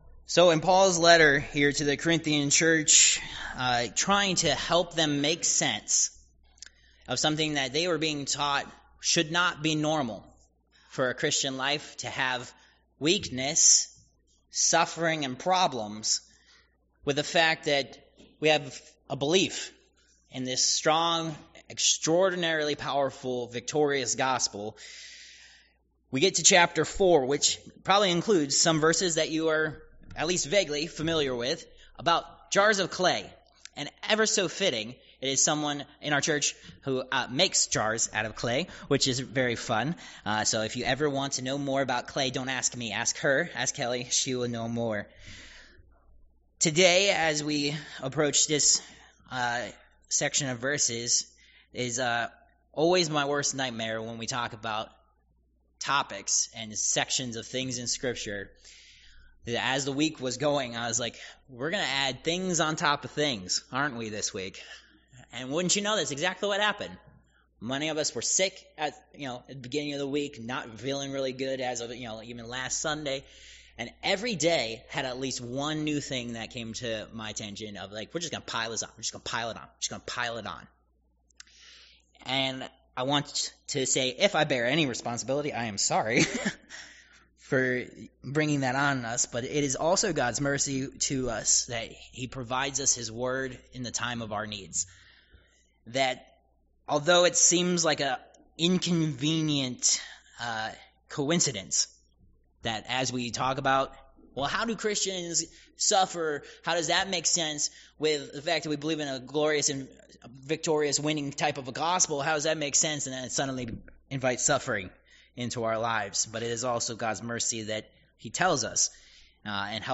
2 Cor. 4:1-18 Service Type: Worship Service « Protected